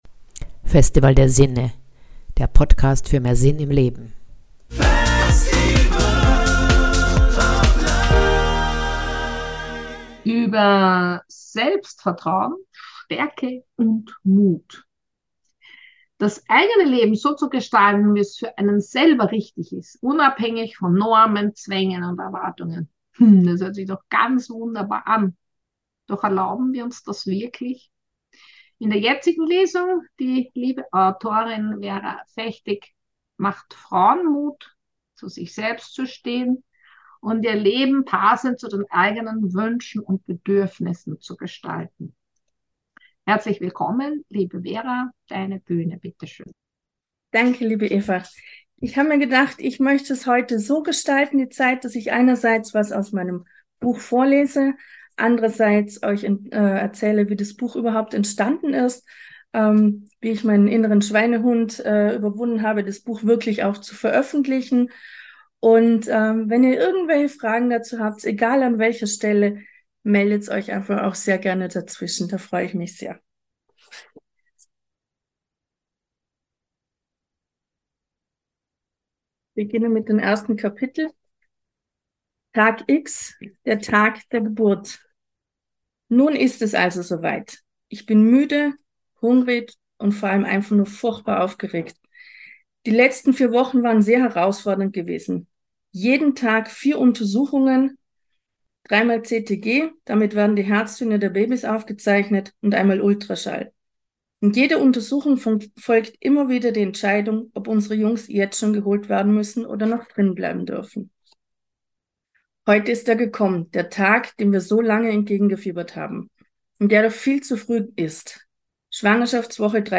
Lesung